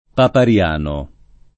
[ papar L# no ]